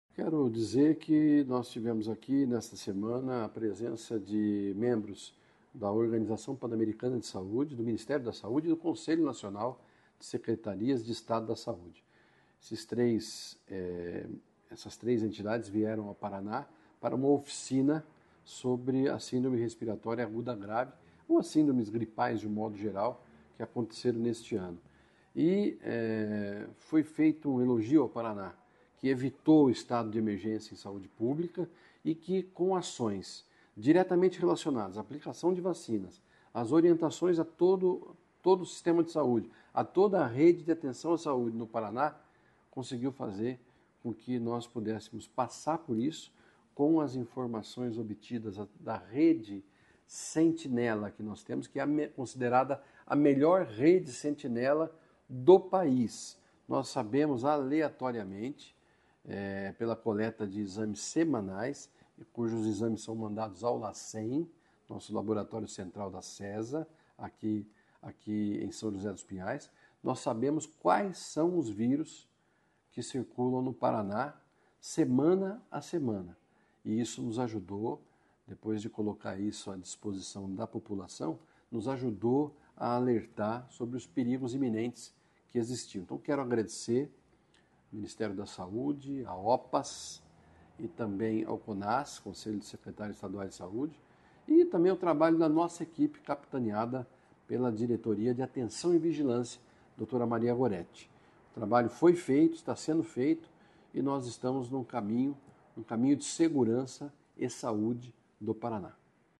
Sonora do secretário Estadual da Saúde, Beto Preto, sobre o Paraná sendo referência na prevenção de Síndromes Respiratórias Agudas Graves